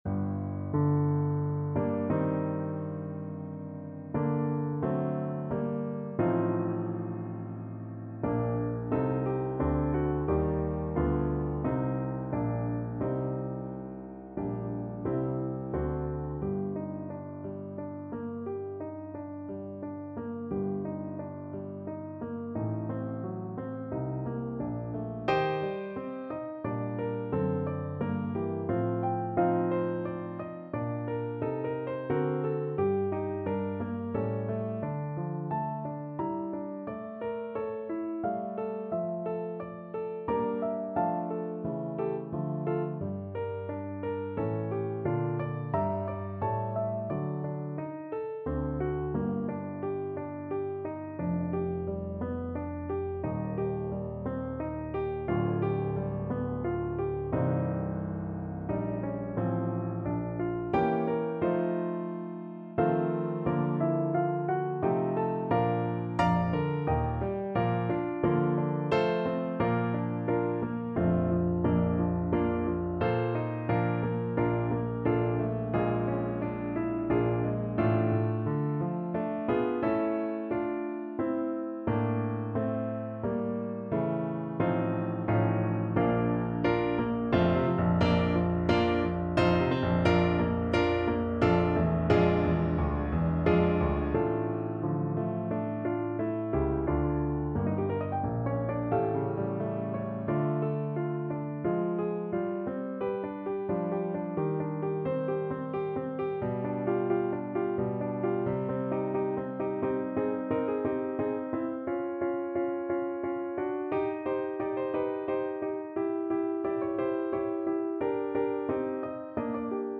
3/4 (View more 3/4 Music)
=88 Nicht schnell =100